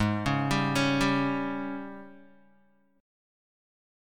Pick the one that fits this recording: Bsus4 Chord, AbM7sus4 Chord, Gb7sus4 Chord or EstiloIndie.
AbM7sus4 Chord